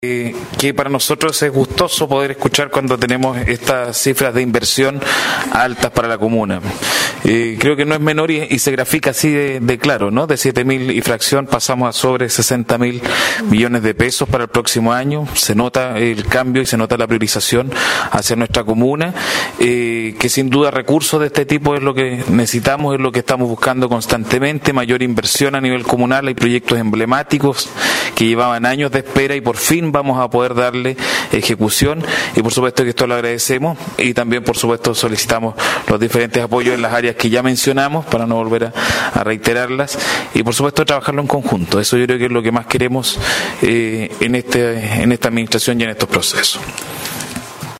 cuna-alcalde-mop.mp3